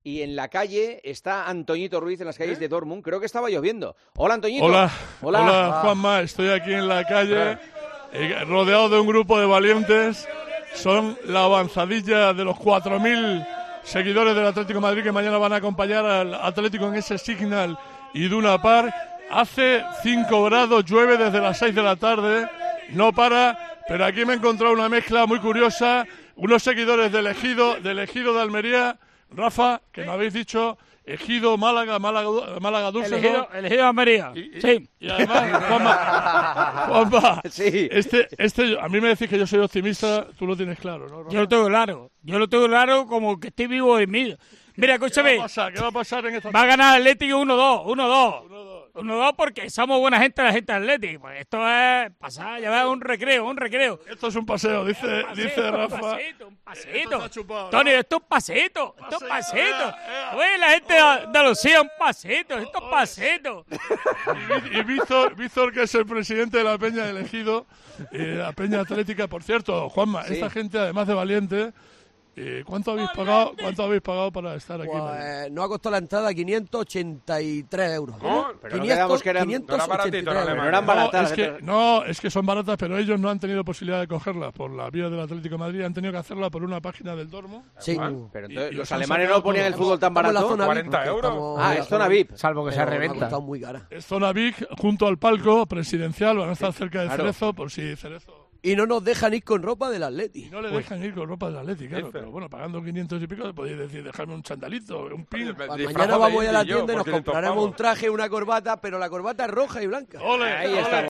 habló con varios aficionados de la Peña Atlética de El Ejido en El Partidazo de COPE que ya estaban en la ciudad para el partido de Champions League